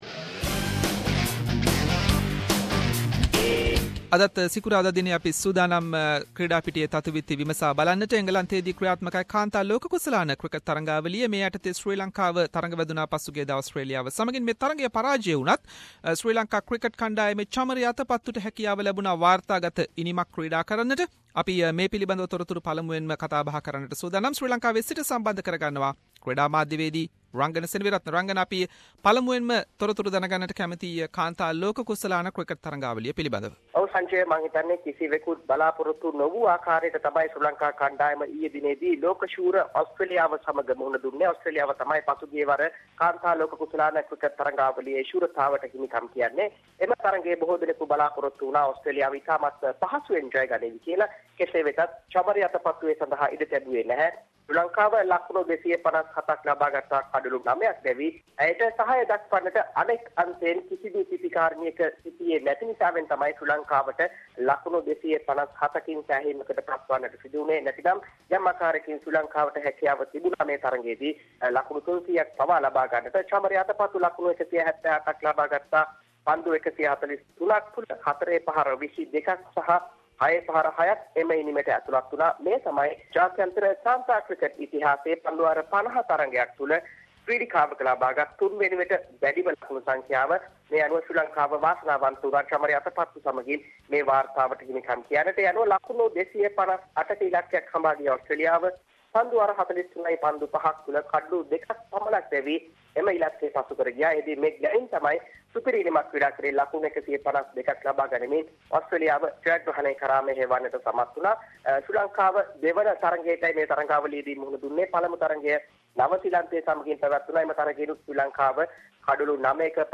In this weeks SBS Sinhalese sports wrap…. Chamari Atapattu hits third-highest score in Women's ODI cricket, Preparation for Zimbabwe cricket tour to Australia, 11-time world champion Manny Pacquiao Vs Jeff Horn boxing game in Brisbane and many more local and international sports news. Sports journalist